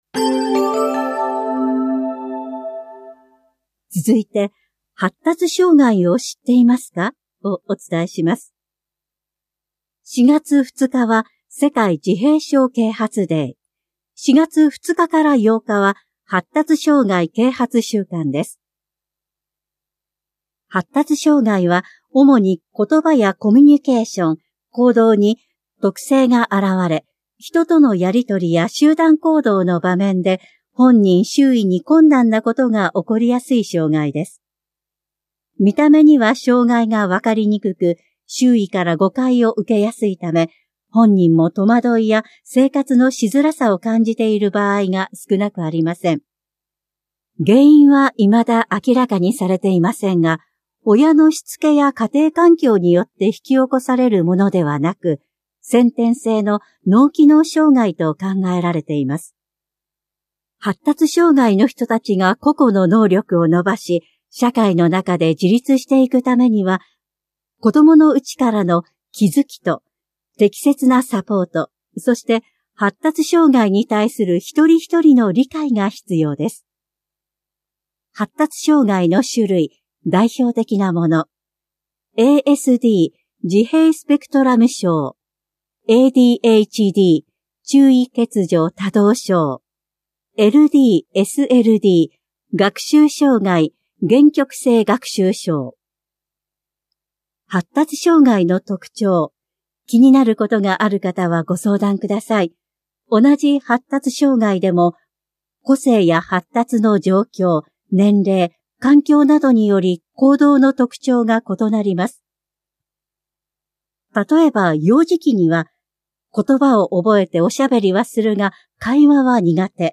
広報「たいとう」令和6年3月20日号の音声読み上げデータです。